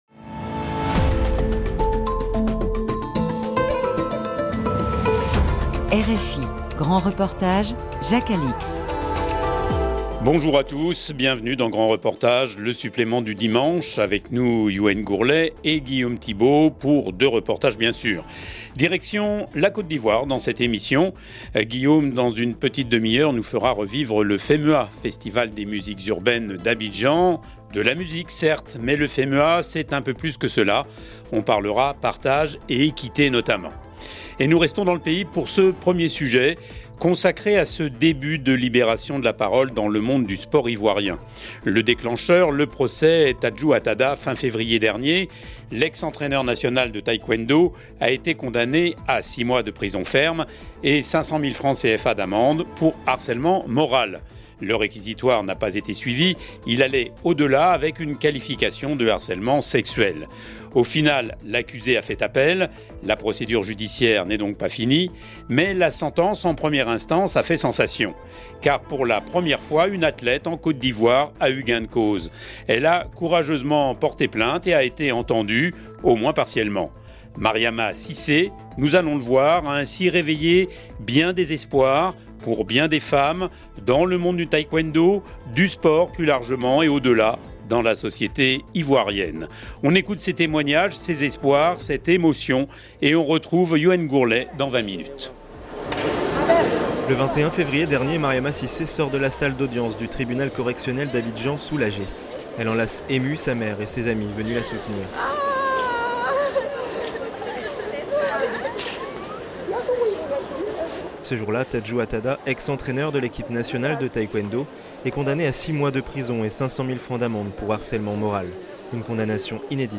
Plongée dans les prisons APAC de la ville de Frutal, dans le Minas Gérais.«